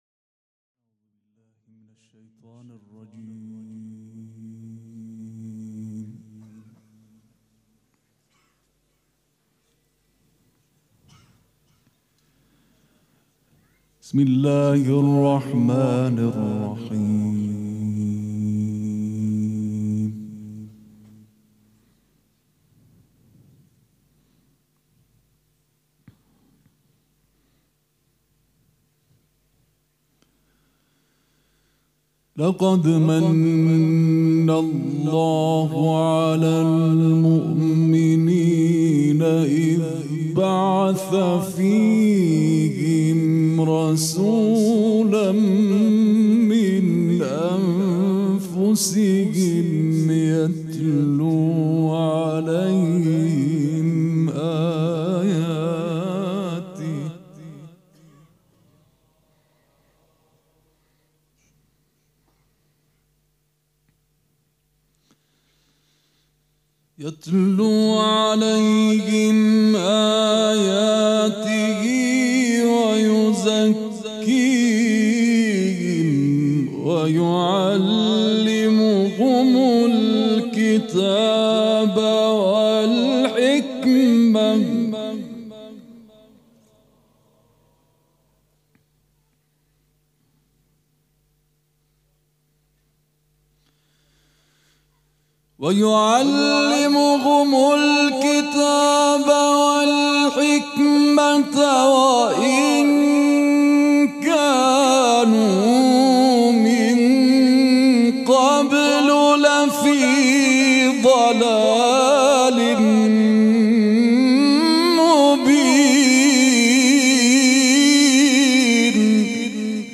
قرائت قرآن کریم
مراسم عزاداری شب اول
quran.mp3